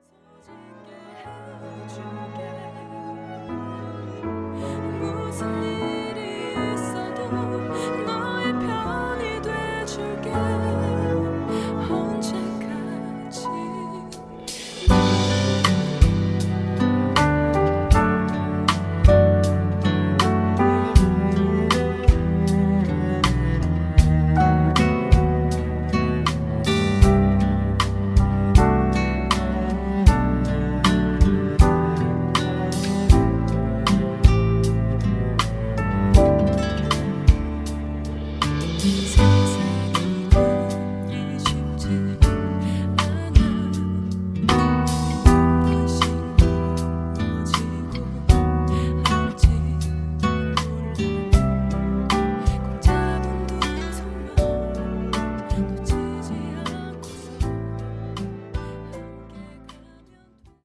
음정 -1키 3:58
장르 가요 구분 Voice Cut
Voice Cut MR은 원곡에서 메인보컬만 제거한 버전입니다.